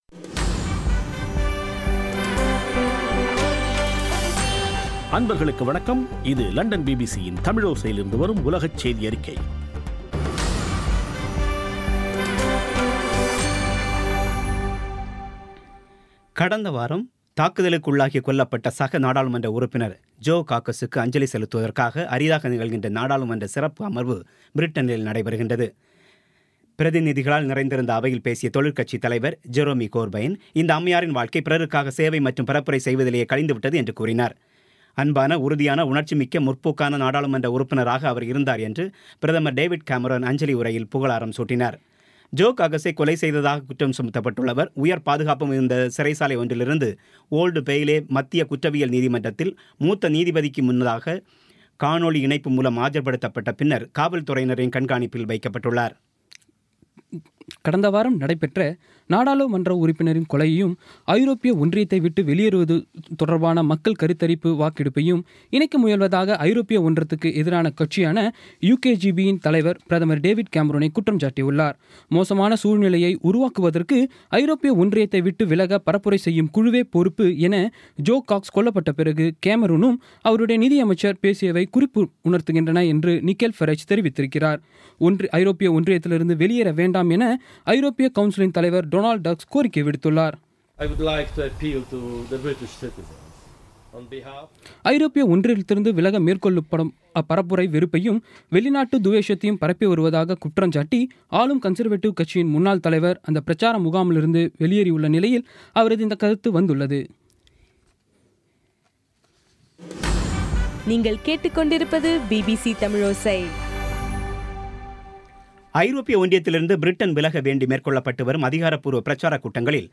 இன்றைய (ஜூன் 20ம் தேதி ) பிபிசி தமிழோசை செய்தியறிக்கை